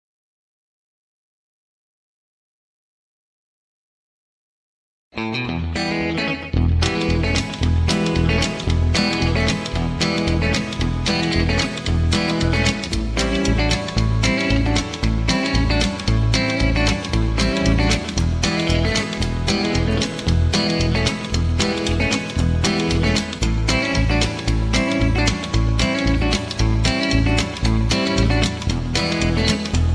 mp3 backing tracks
easy litstening, r and b, rock & roll